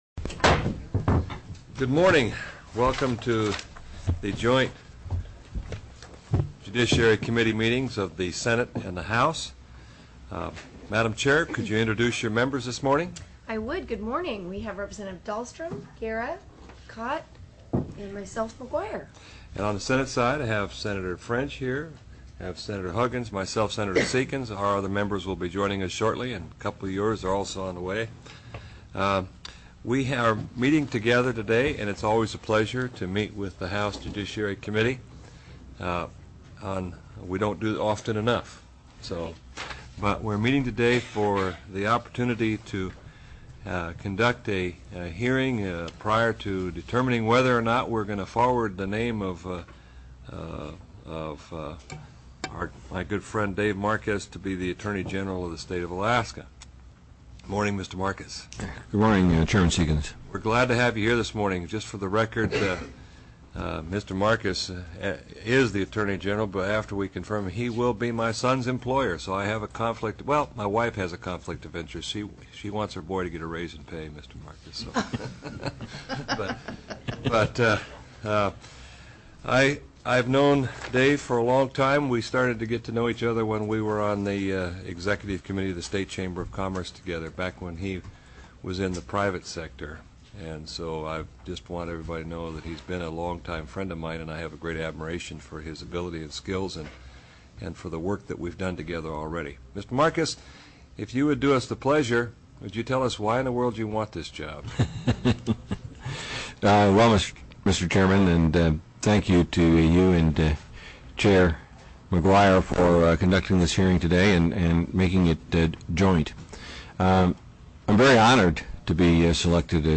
TELECONFERENCED Attorney General Confirmation Hearing: David Marquez